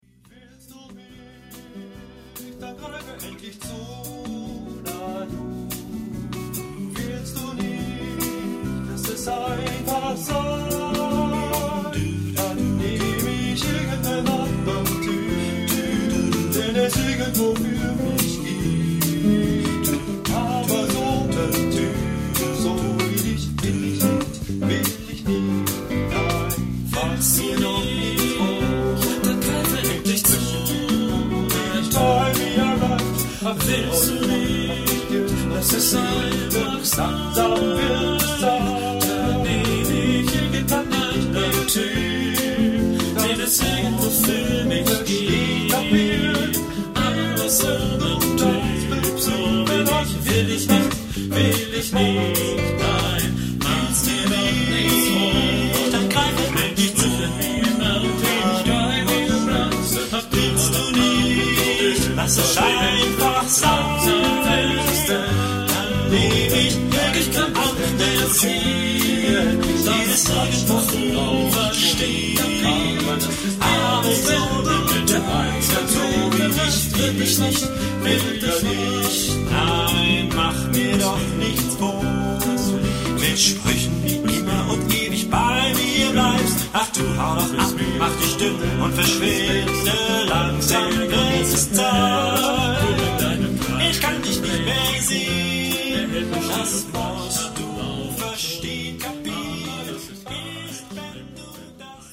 Género/Estilo/Forma: Canon ; Profano
Tipo de formación coral:  (3 voces iguales )
Tonalidad : do mayor